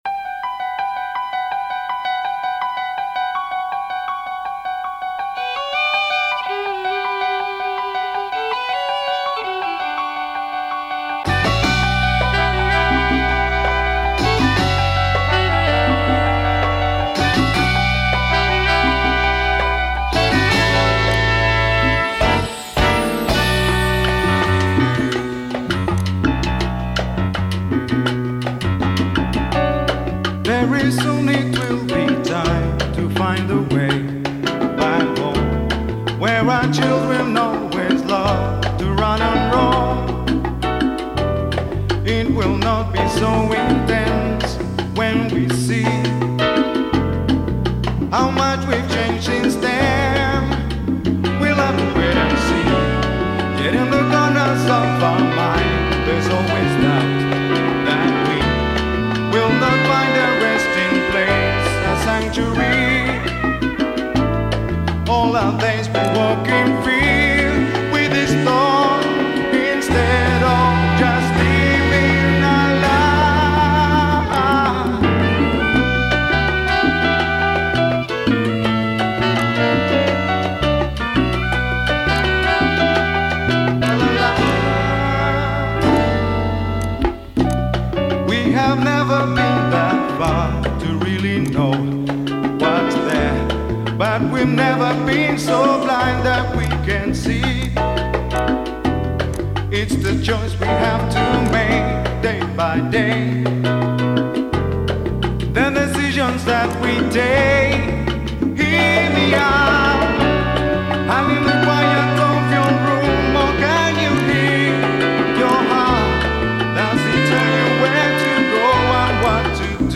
Still, I love the horn arrangements even to this day